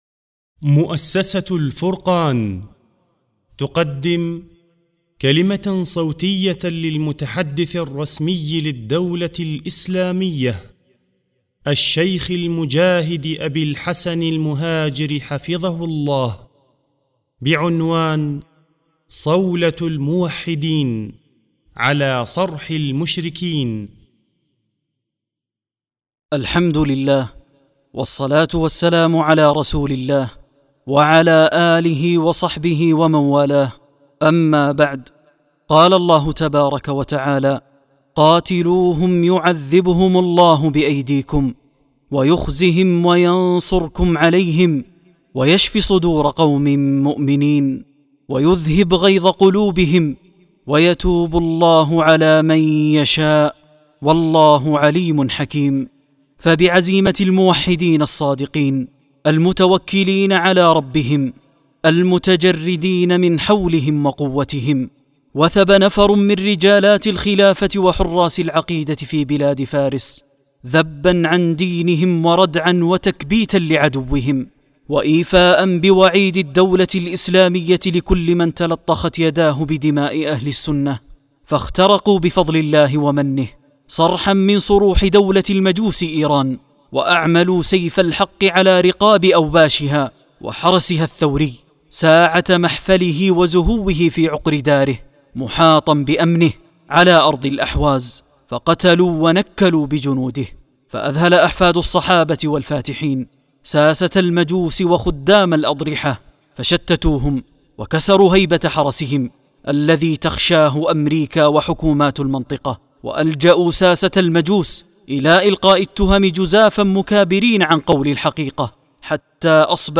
+ Речь пресс-секретаря ИГ